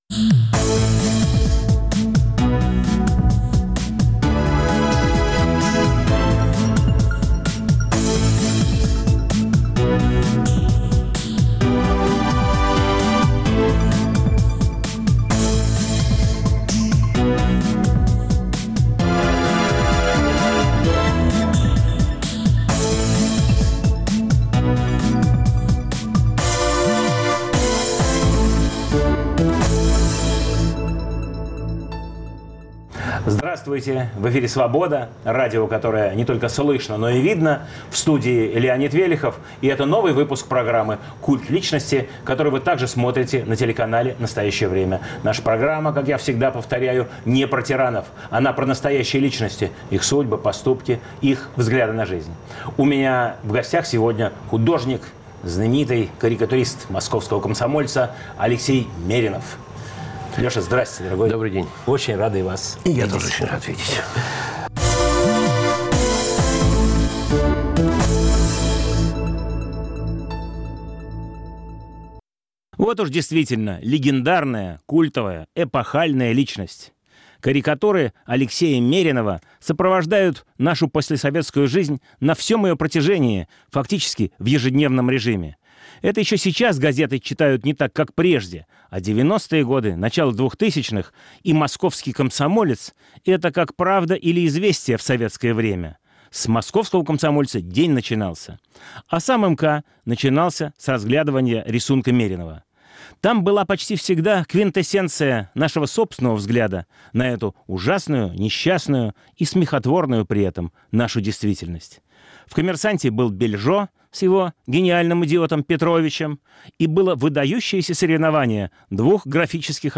В студии нового выпуска программы – легендарный карикатурист «Московского комсомольца».